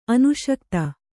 ♪ anuśakta